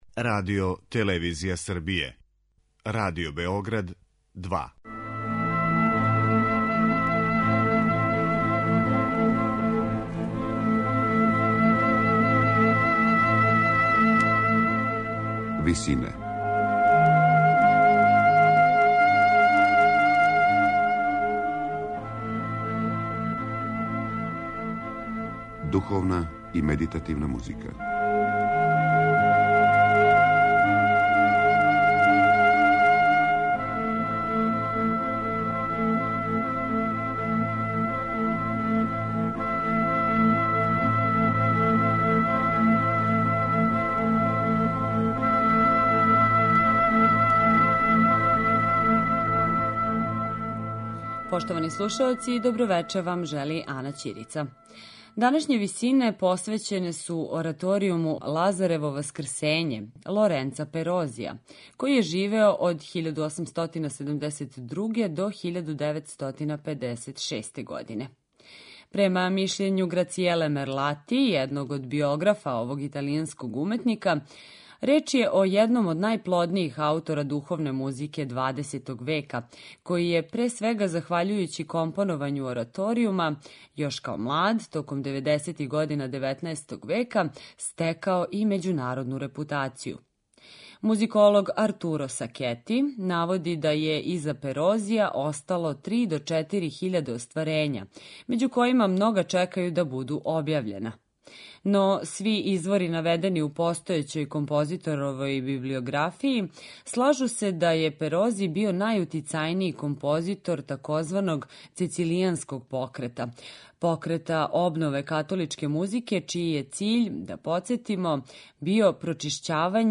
Лоренцо Перози: ораторијум 'Лазарево васкрснуће' (1898)